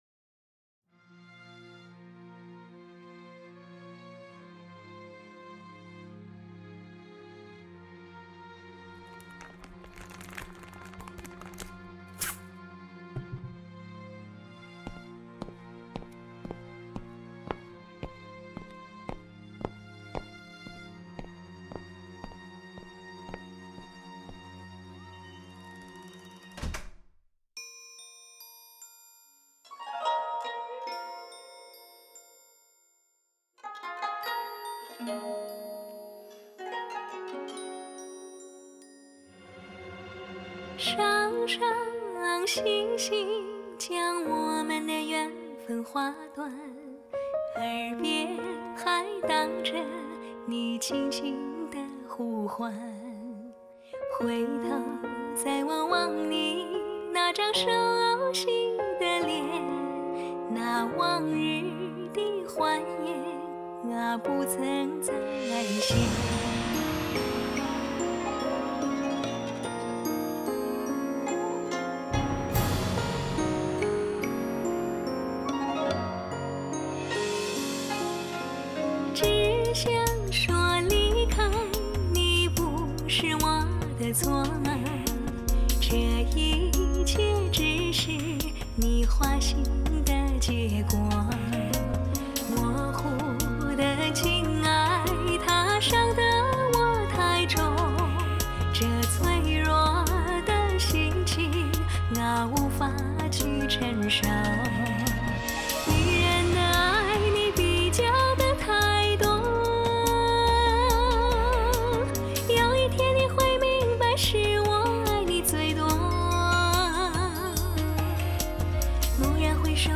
清幽中——蜕变，恬谧，豁达，纯美，乾净，悠扬，动情。